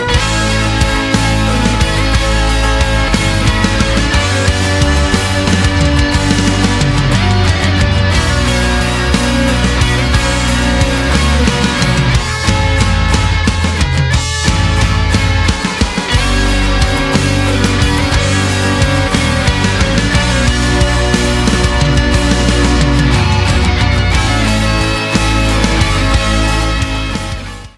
• Качество: 192, Stereo
Классный проигрыш на электрогитарах